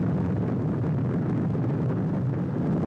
smelter.ogg